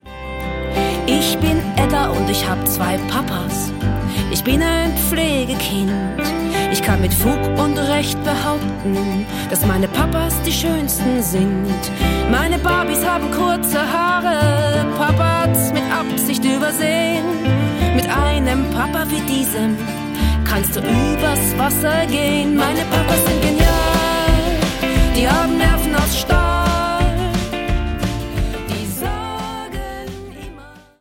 Coole Mucke für Mädchen und Jungs
Kinder- / Jugendbuch Gedichte / Lieder